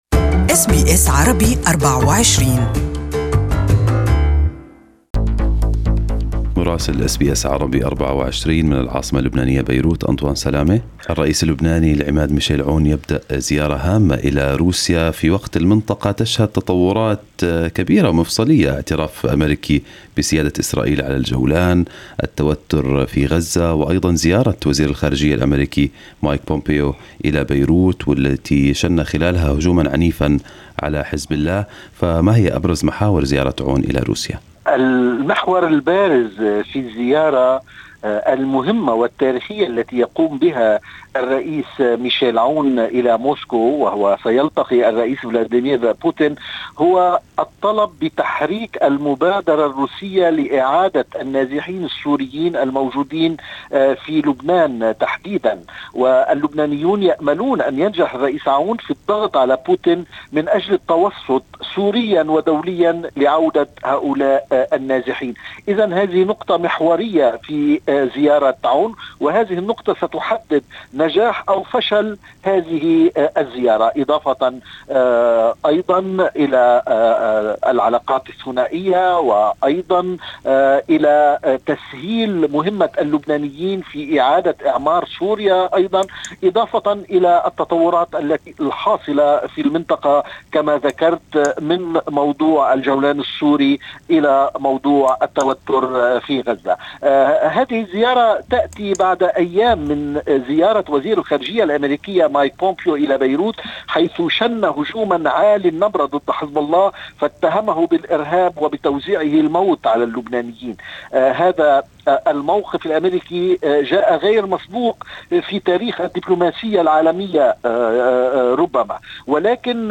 Our correspondent in Beirut has the details
Listen to the full report from Beirut in Arabic above